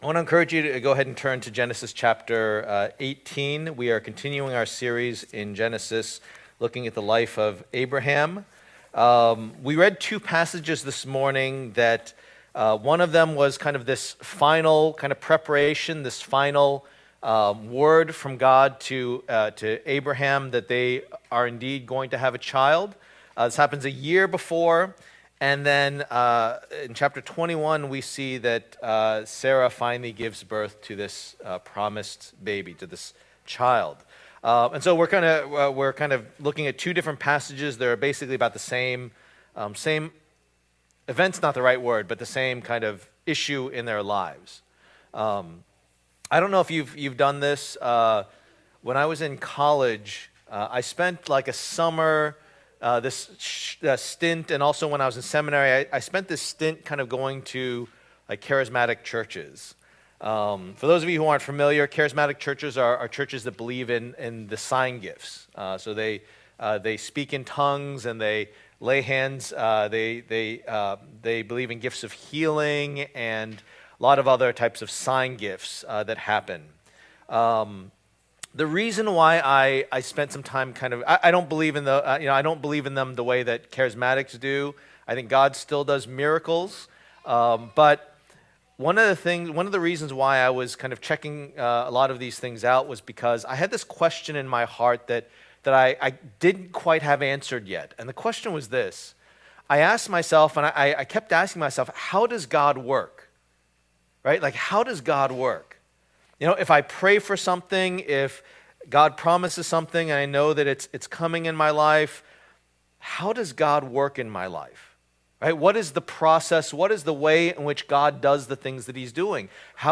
The Gospel According to Abraham Passage: Genesis 18:1-15, Genesis 21:1-7 Service Type: Lord's Day